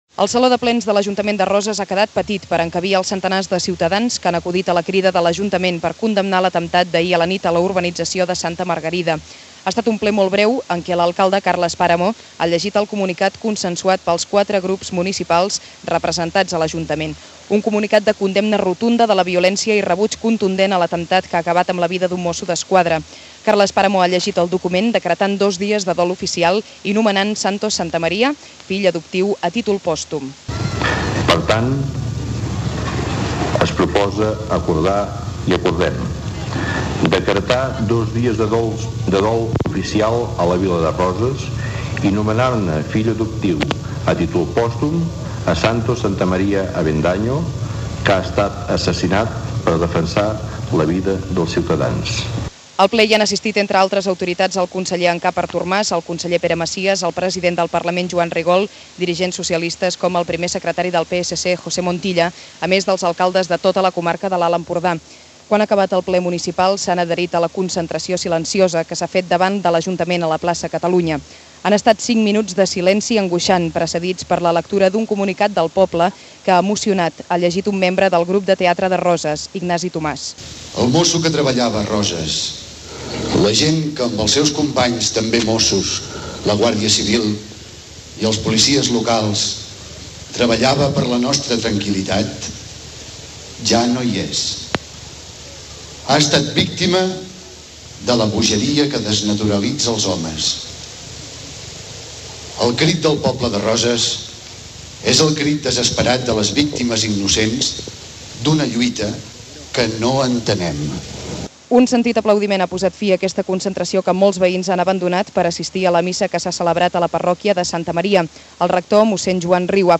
Informatius: crònica de l'atemptat de Roses - Ràdio Girona, 2001